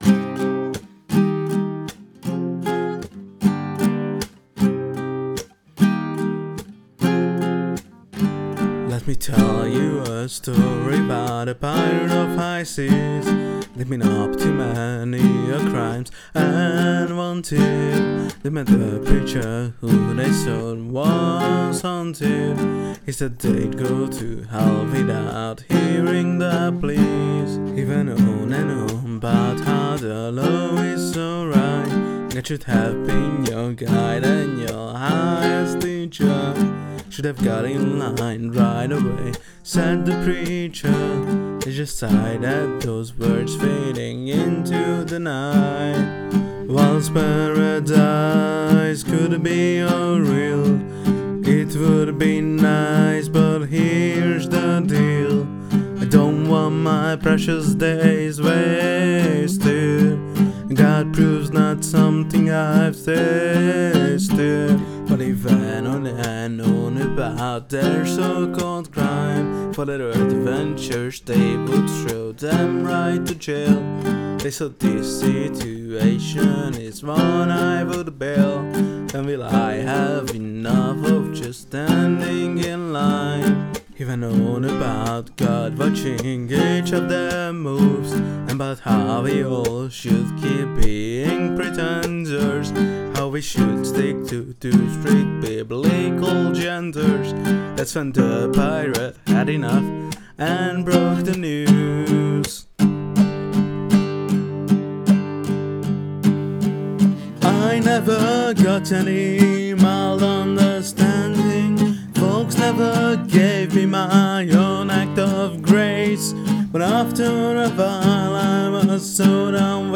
not gonna get a better recording with my dysfunctional ass! kinda wanted to try 3/4